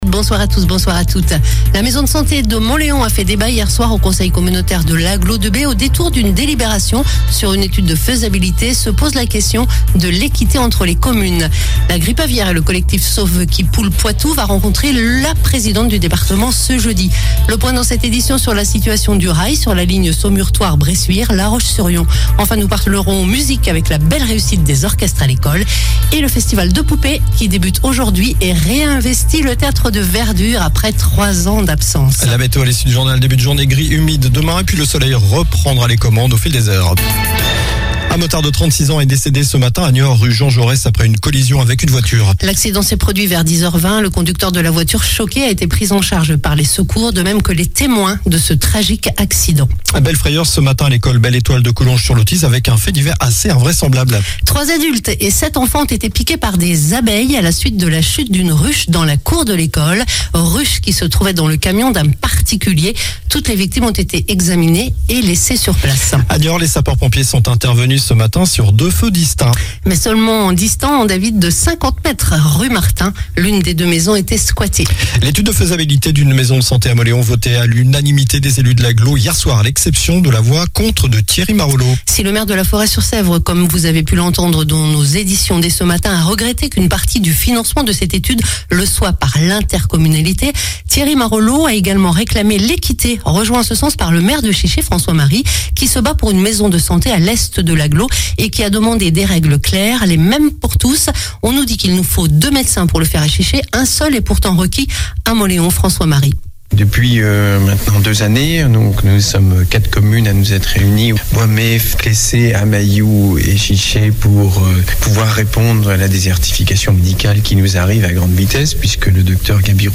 Journal du mercredi 29 juin (soir)